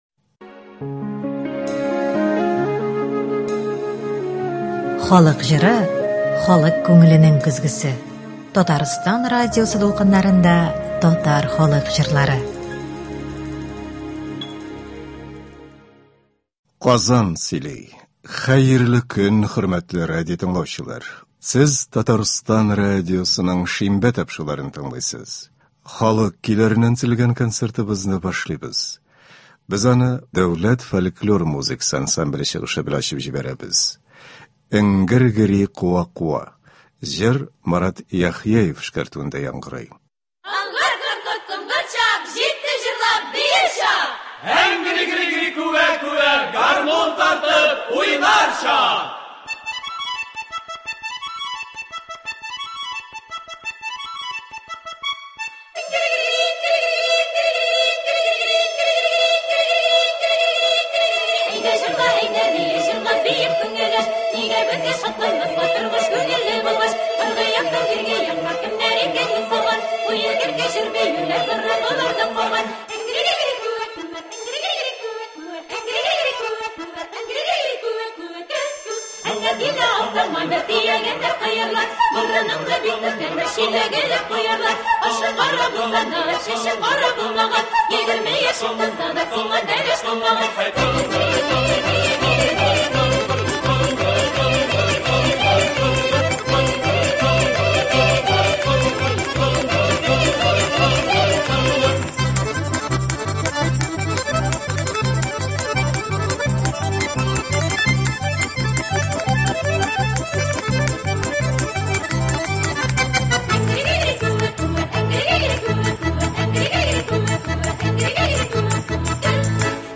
Татар халык җырларын тыңлагыз.